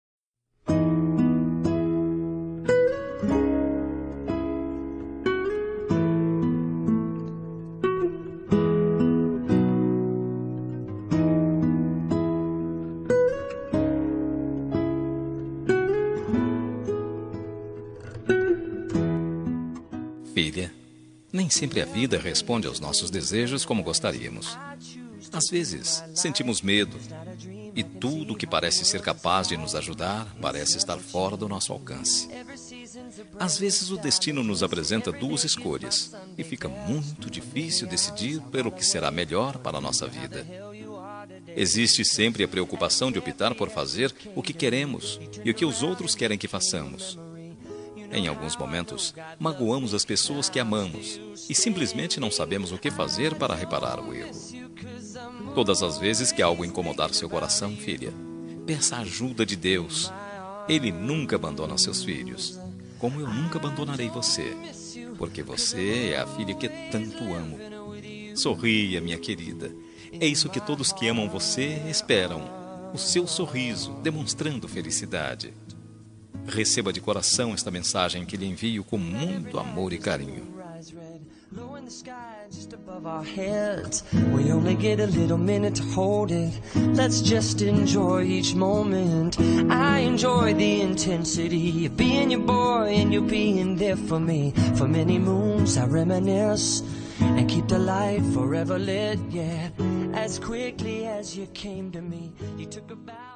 Homenagem para Filha – Voz Masculina – Cód: 8137